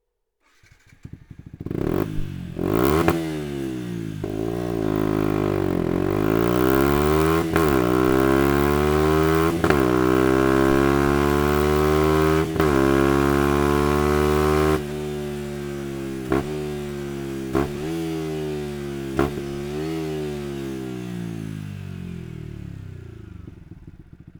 Sound Akrapovic Slip-On